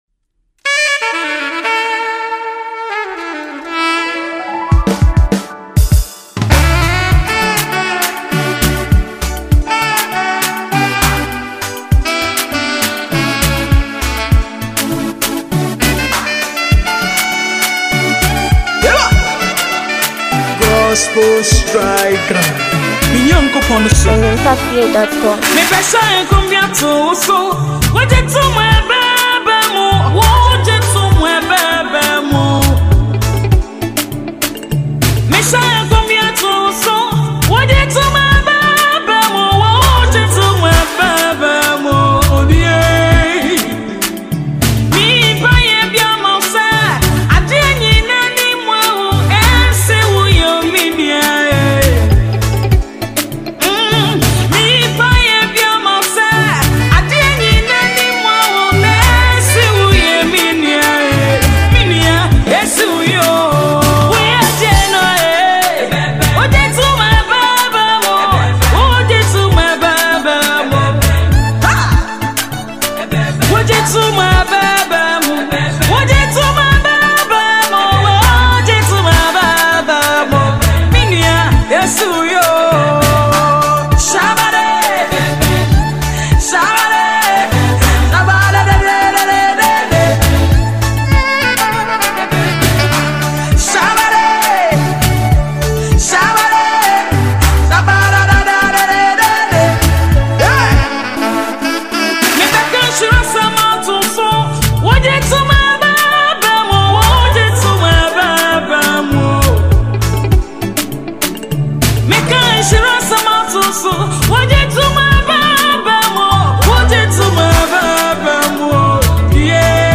Gospel Music
Ghanaian Gospel